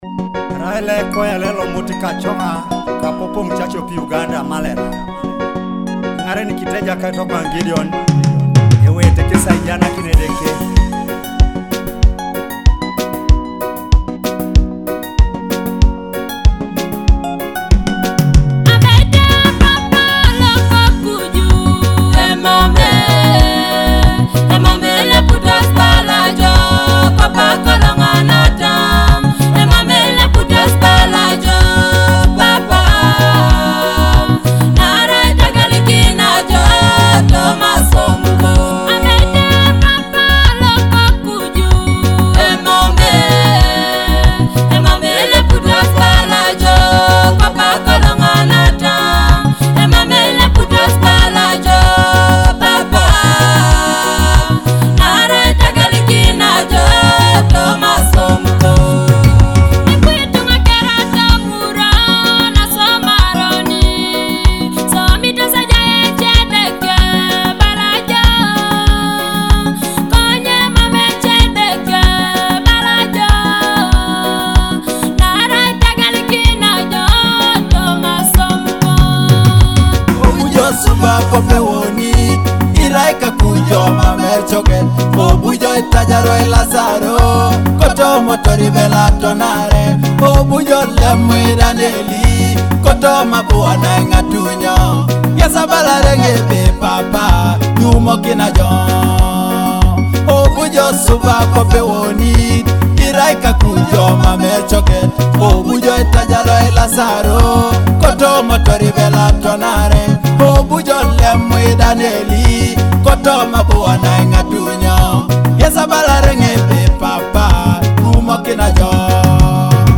along with top tracks and trending gospel music